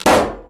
Balloon-Burst-05-edit2
balloon burst pop sound effect free sound royalty free Memes